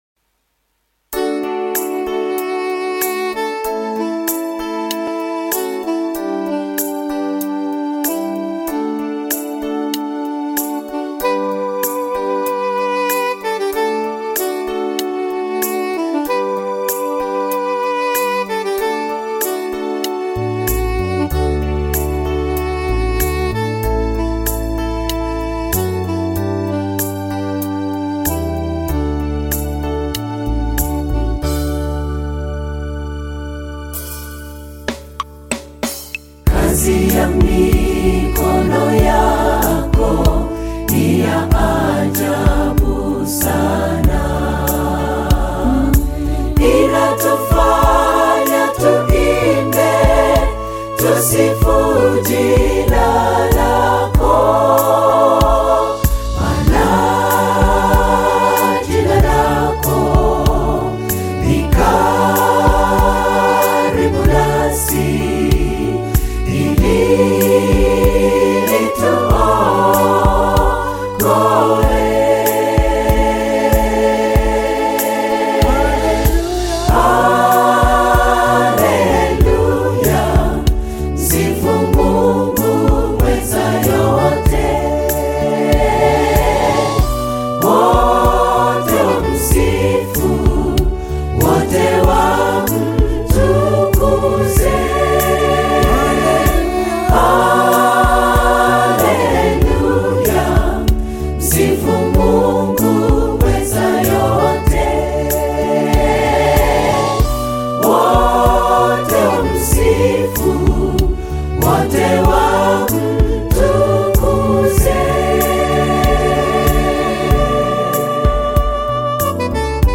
Tanzania SDA Gospel choir
gospel song
African Music